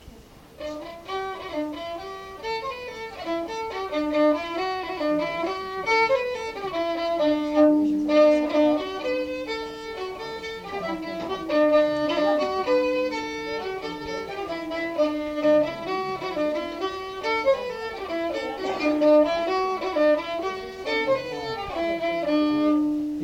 Aire culturelle : Couserans
Lieu : Castillon-en-Couserans
Genre : morceau instrumental
Instrument de musique : violon
Danse : castanha